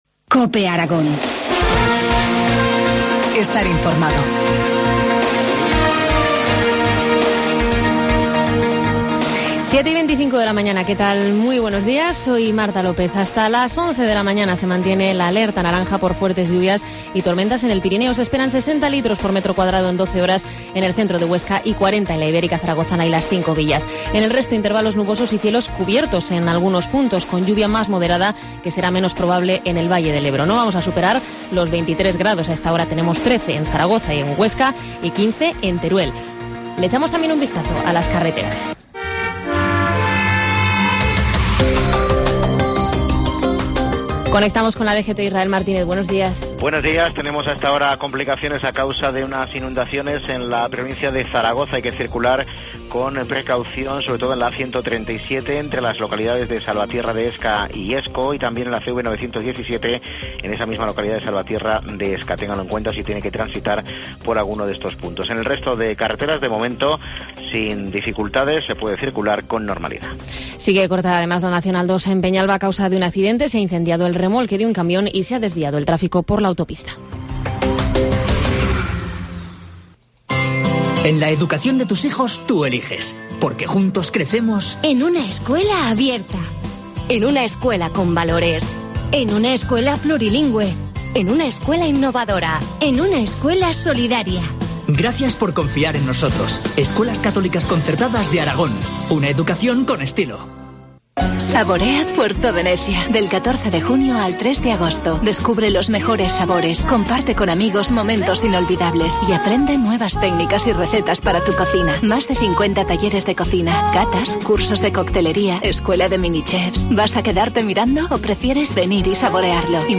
Informativo matinal, miércoles 19 de junio, 7.25 horas